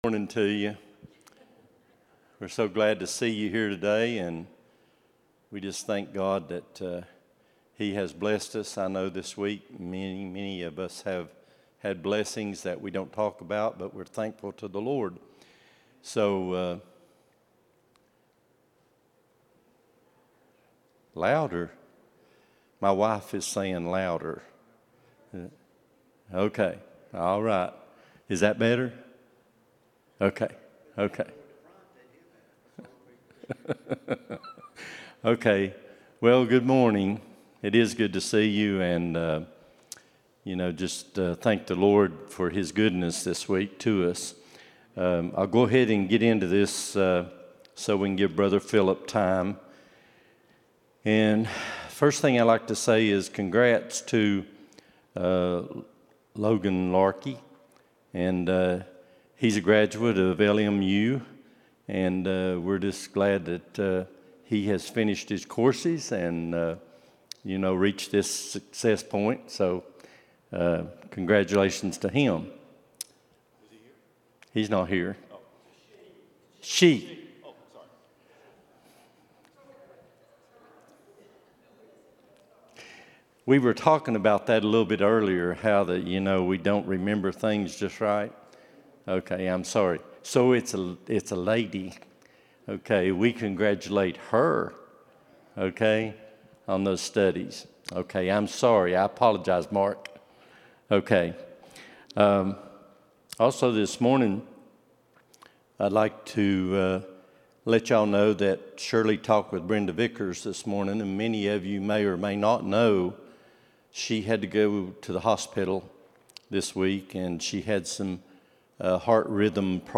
12-22-24 Sunday School | Buffalo Ridge Baptist Church